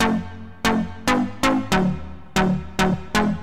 大房间里的落地铅
描述：大房间的房子下降的合成器线索。
Tag: 128 bpm Dirty Loops Synth Loops 1.26 MB wav Key : G